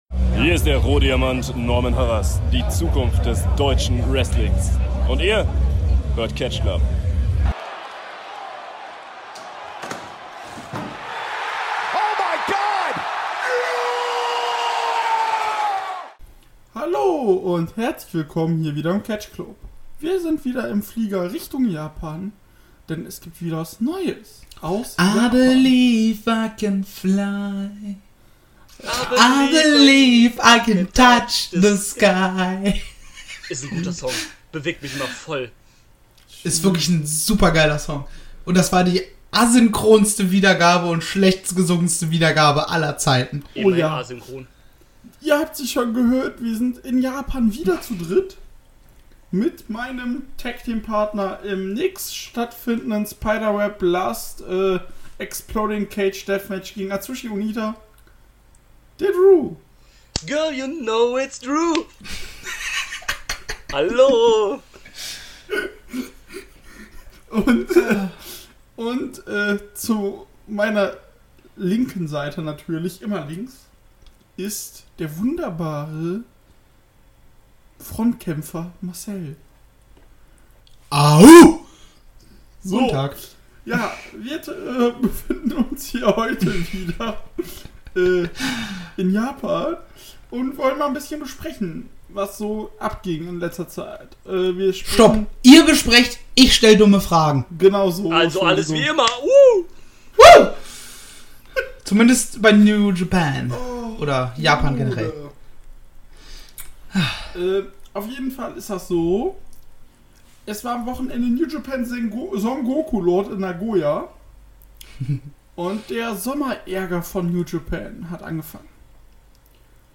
Beschreibung vor 5 Jahren Die drei Jungs sitzen wieder im Flieger gen Japan um über die jüngsten Geschehnisse bei Sengoku Lord von New Japan zu reden. Außerdem geht es um die “Fusion” von DDT & Noah sowie einen kurzen Ausblick auf den NJPW Summer Struggle 2020.
Dadurch hat die Aufnahme seine Spur nicht die gewohnte Qualität und ein leises Rauschen im Hintergrund.